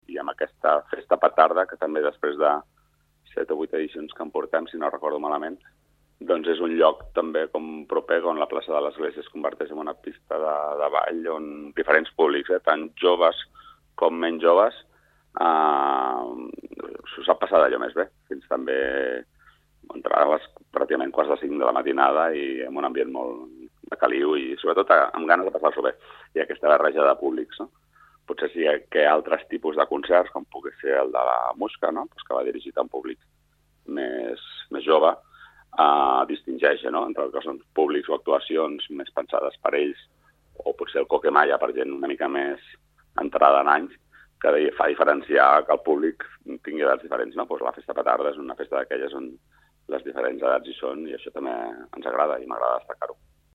Sergi Corral, regidor de Cultura de l'Ajuntament de Martorell